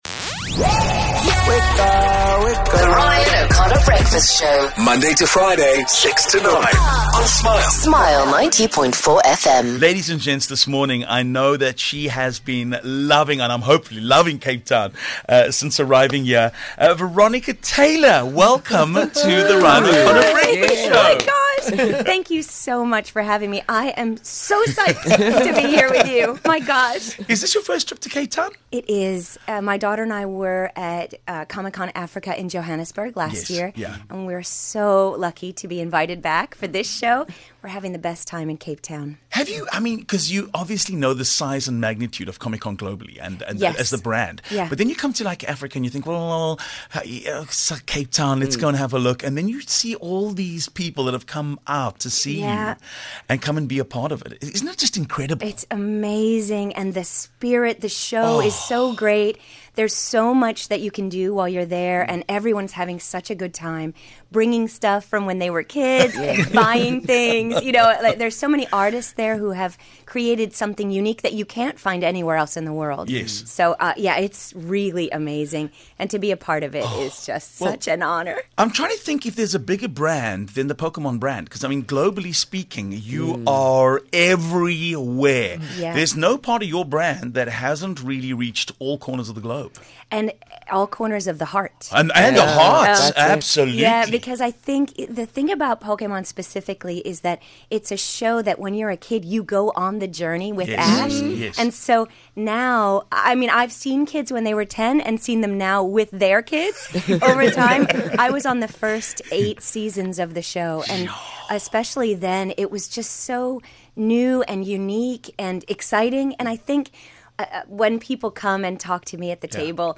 One of the special guests this year is Veronica Taylor who voiced the role of Ash Ketchum in Pokemon. Veronica joined us in studio.